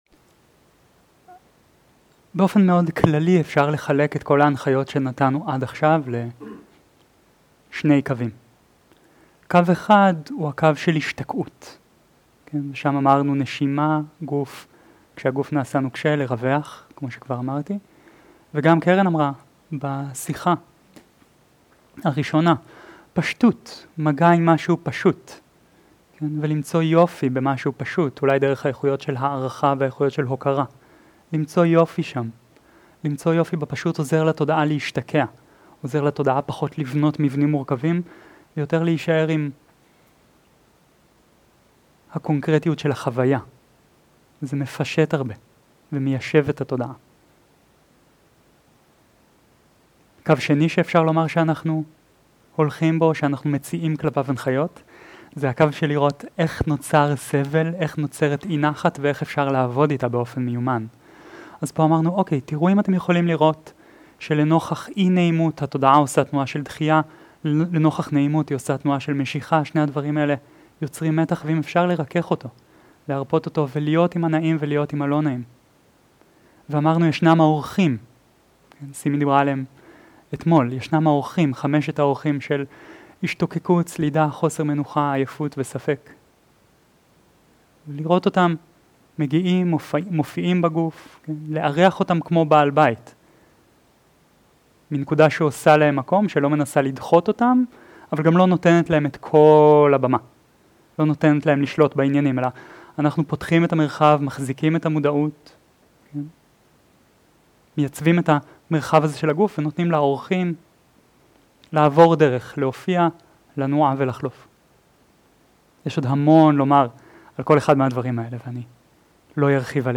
שיחות דהרמה
איכות ההקלטה: איכות גבוהה